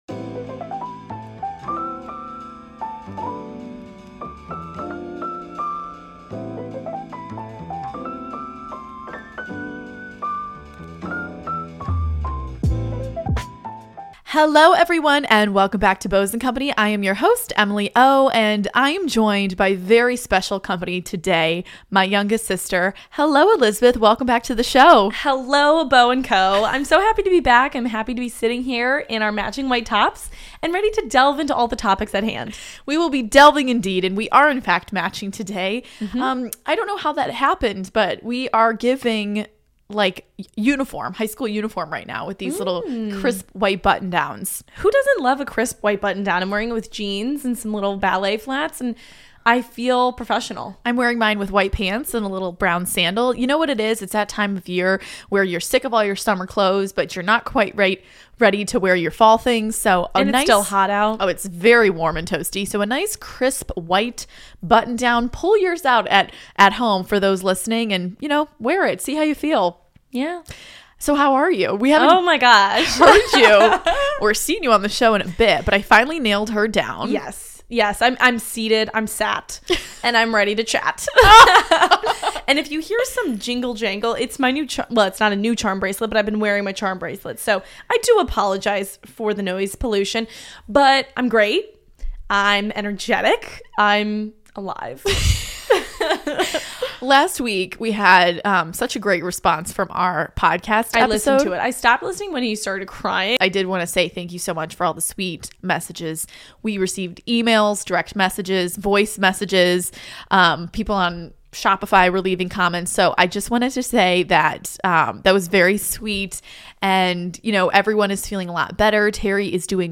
Expect lots of laughs, gabbing, and sisterly bonding!